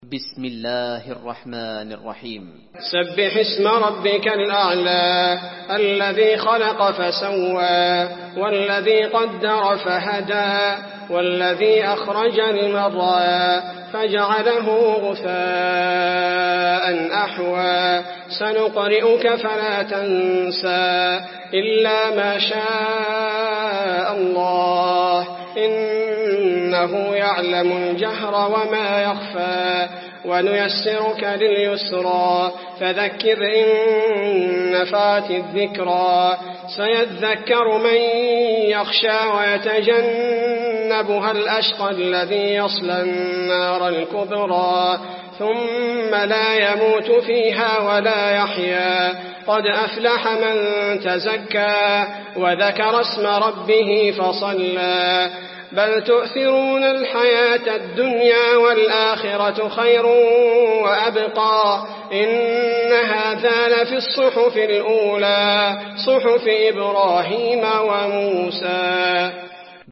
المكان: المسجد النبوي الأعلى